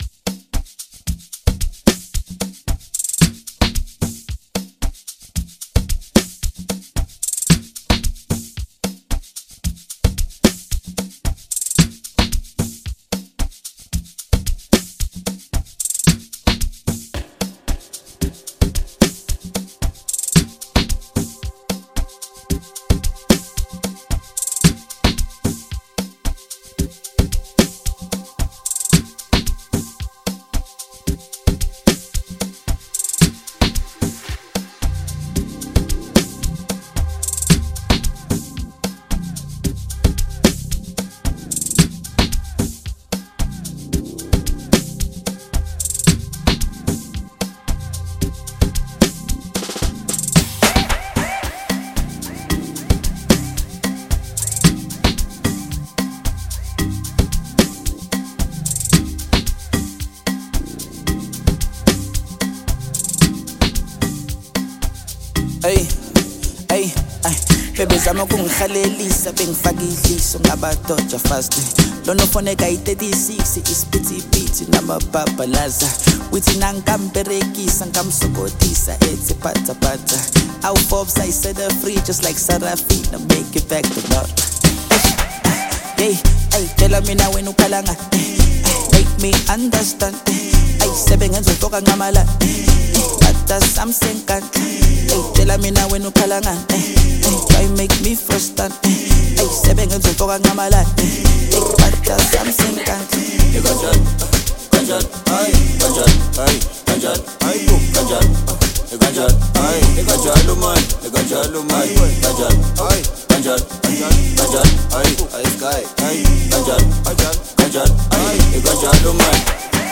With its infectious beat and seamless chemistry
stands out as a feel-good anthem